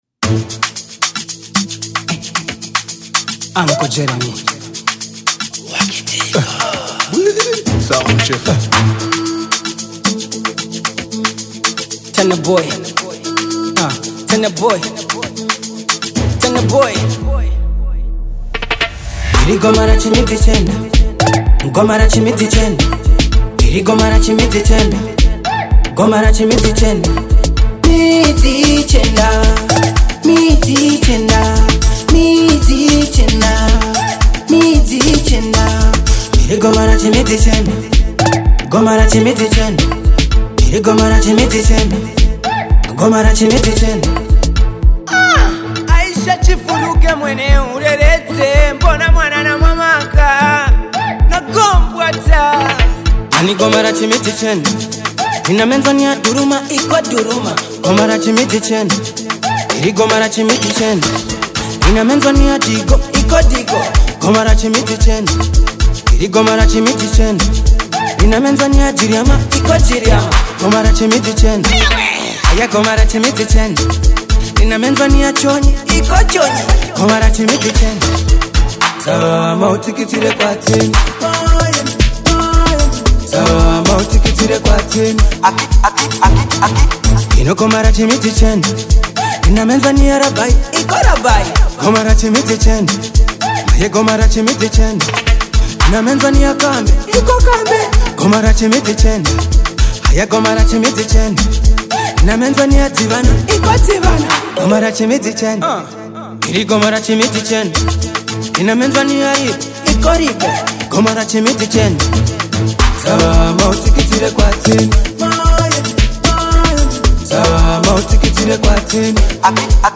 Amapiano track